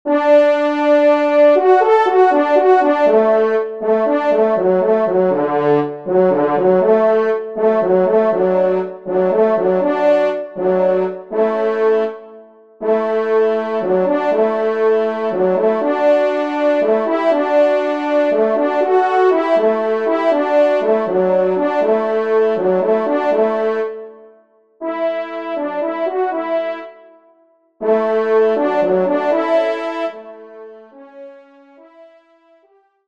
Genre :  Divertissement pour Trompes ou Cors
Pupitre 2° Cor